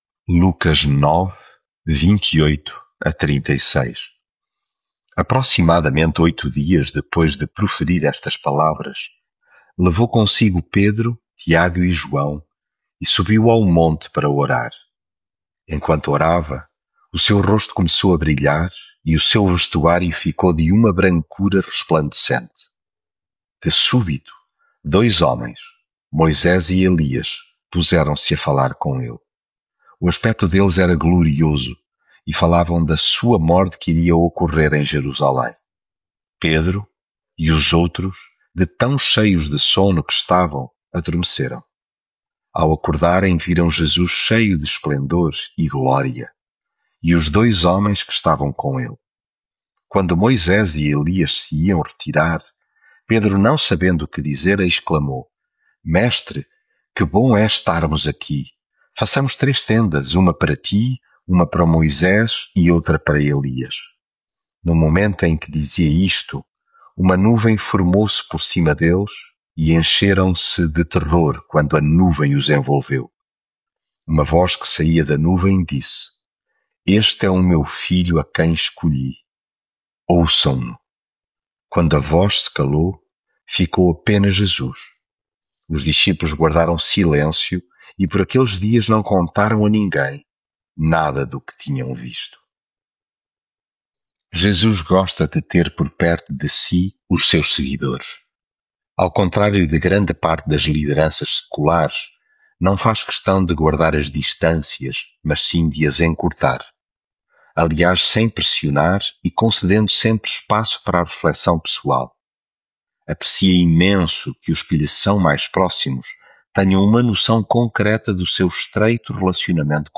devocional Lucas leitura bíblica Aproximadamente oito dias depois de proferir estas palavras, levou consigo Pedro, Tiago e João e subiu ao monte para orar.
Devocional